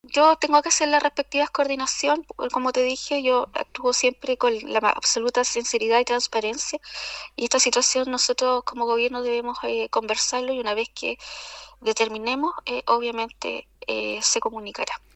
En entrevista con Radio “Sago”, la delegada presidencial en la provincia de Osorno, Claudia Pailalef, se refirió al desalojo registrado anoche en la comuna de Puerto Octay.